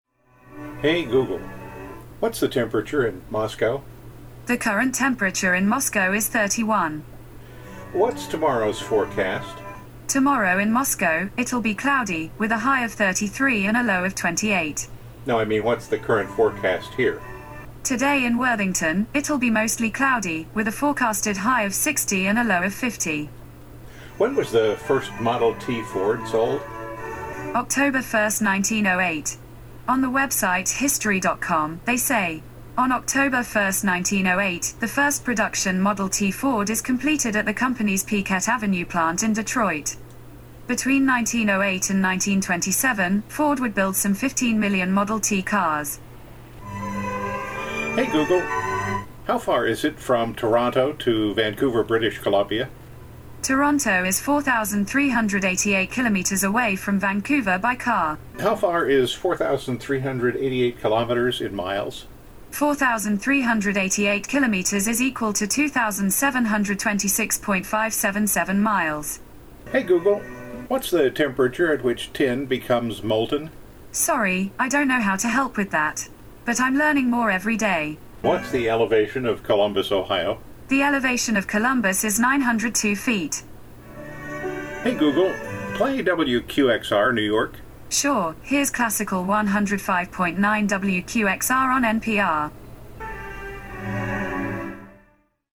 (449.5 degrees Fahrenheit) You'll notice that my Google assistant sounds British.